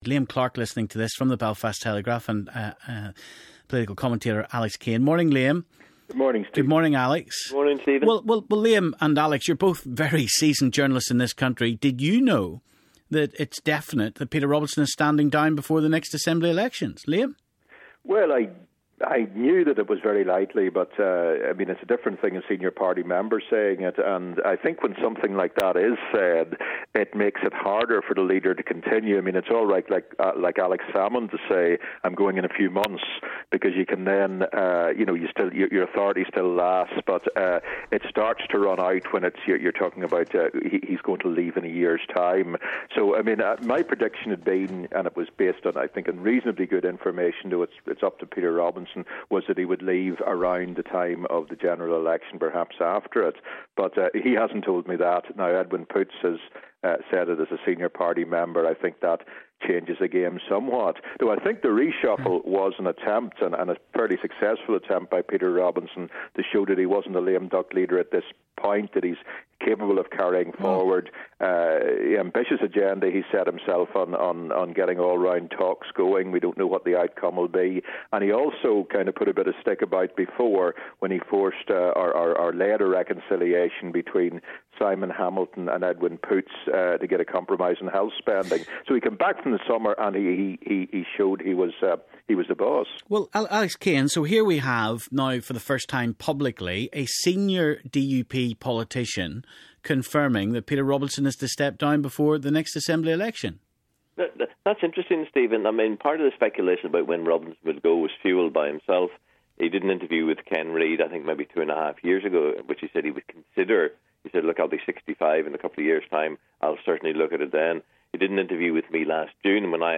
Political journalists